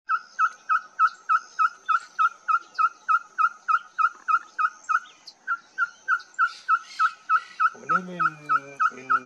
Ferruginous Pygmy Owl (Glaucidium brasilianum)
Life Stage: Adult
Detailed location: Reserva Natural Laguna de Utracán
Condition: Wild
Certainty: Filmed, Recorded vocal